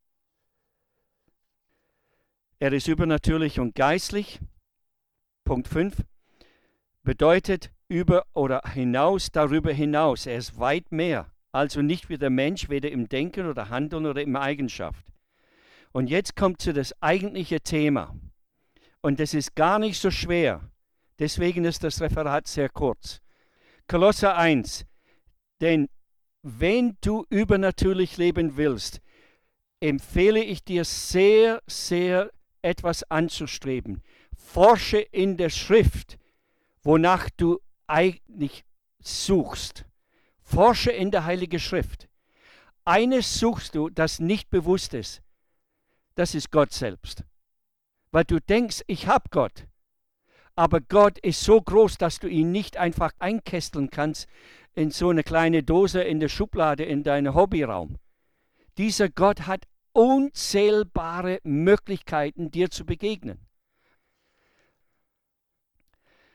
Referent